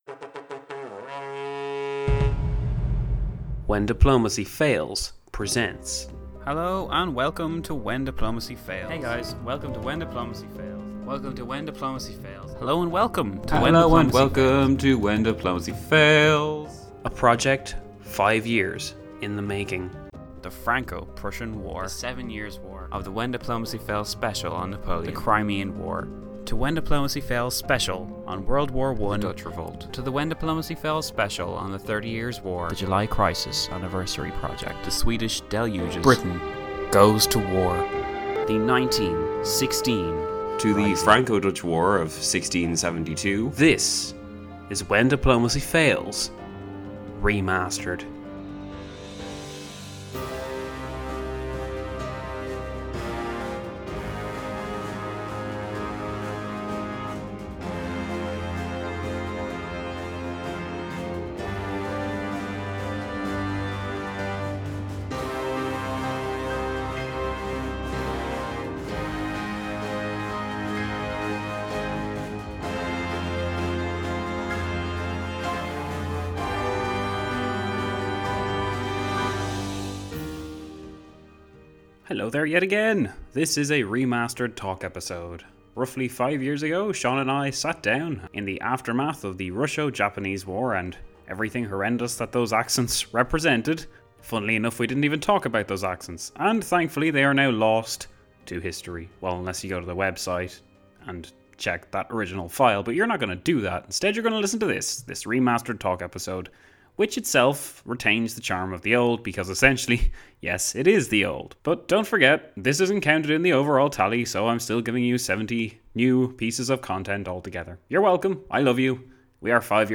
Nothing new here per se, but it is a great chance to listen to two friends shooting the breeze on history if you have yet to do so, and of course it's a great exercise in listening for everyone else besides, so give it a listen now!